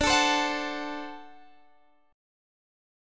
D7 Chord
Listen to D7 strummed